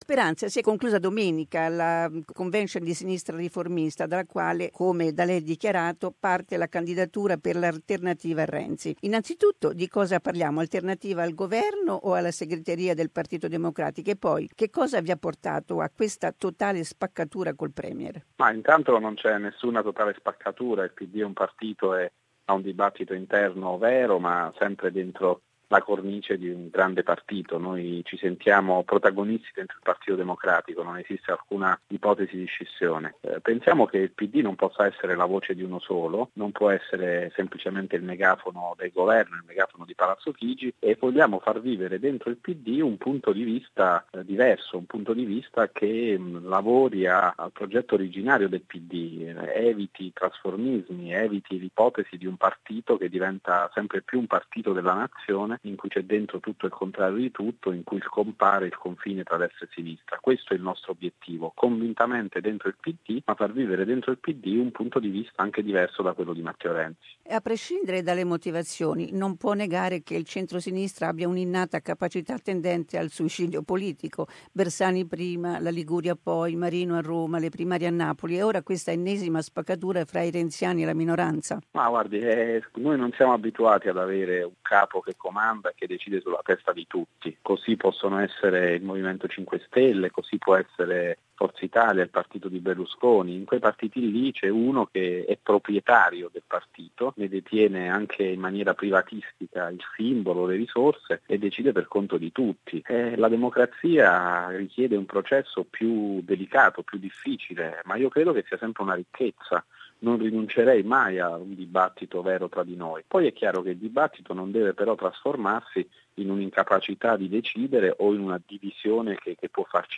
We spoke with Democratic Party MP, Roberto Speranza about the three-day convention promoted by the Left Reformist Democratic Party in Perugia.